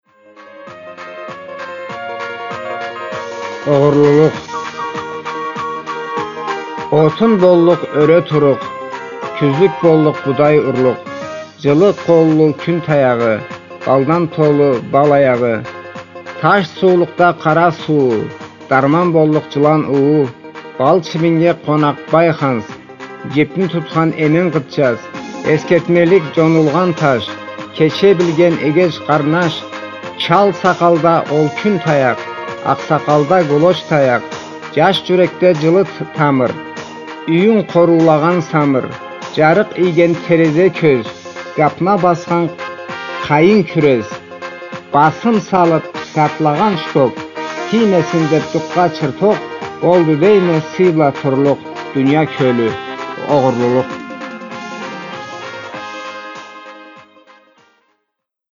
назму